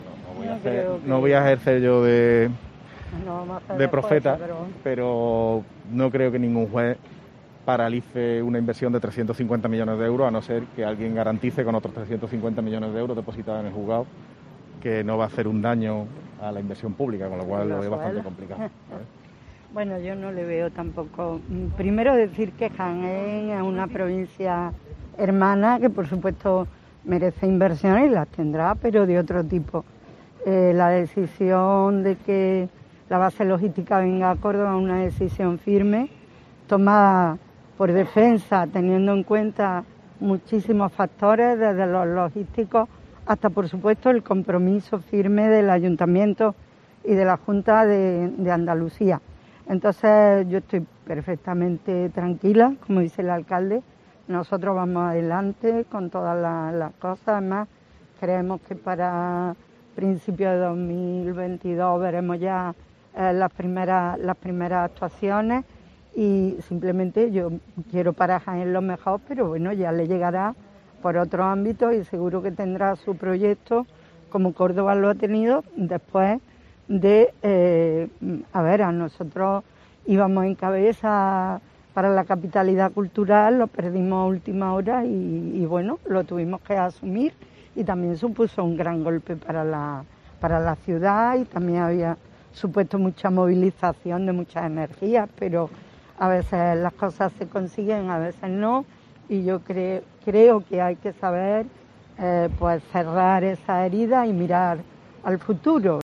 José María Bellido, alcalde de Córdoba y Rafaela Valenzuela, subdelegada del Gobierno en esa provincia